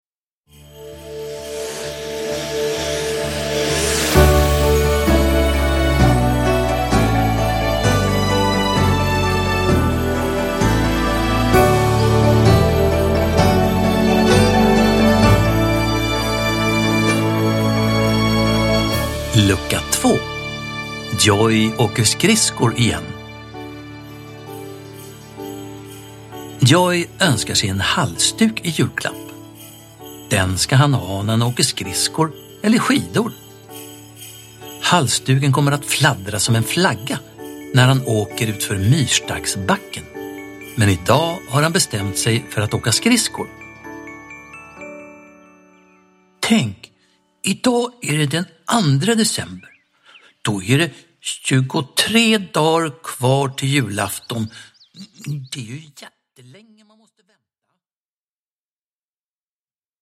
Produkttyp: Digitala böcker
Uppläsare: Staffan Götestam